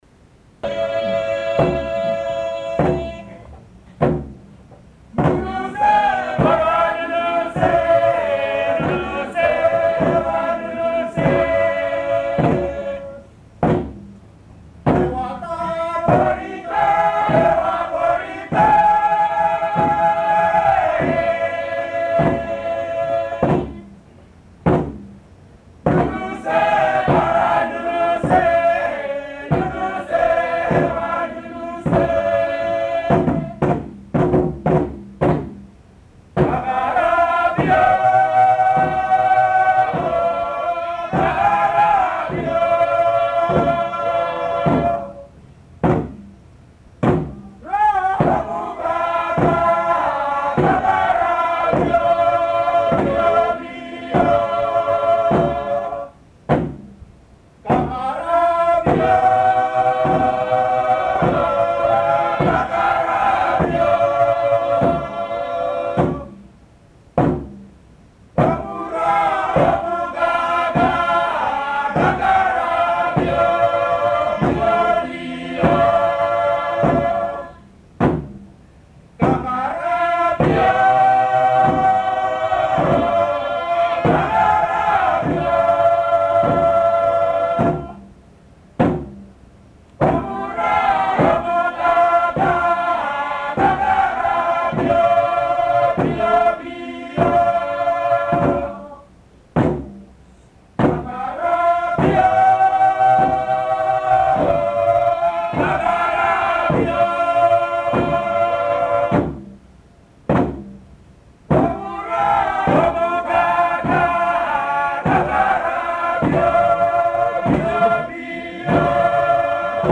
I recorded some things in Papua New Guinea 40+ years ago. I don't know where this was recorded or who it is, but it comes up in my Apple Music because Apple Music adds files it sees on the computer, and I got this old tape converted to mp3s a while ago. So here for your listening pleasure are some people in PNG in 1984.